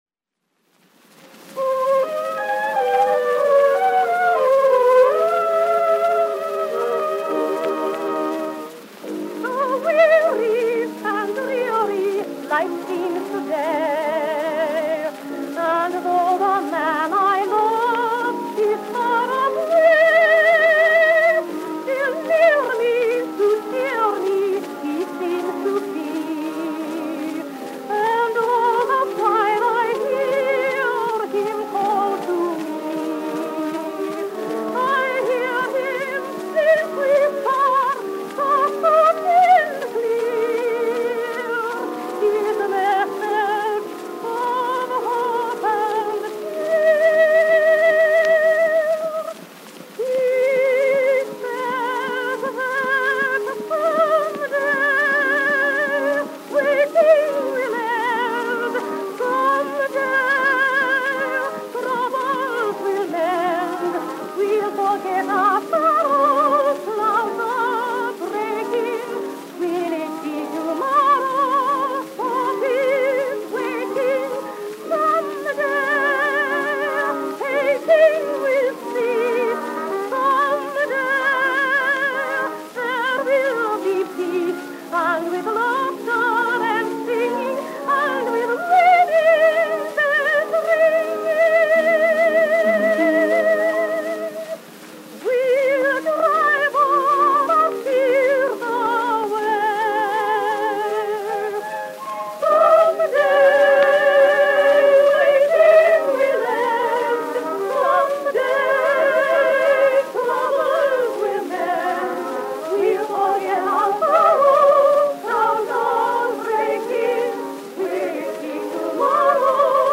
Our collection is comprised of wax cylinder donations from many sources, and with the digitization process being fully automated, not all listed contents have been confirmed.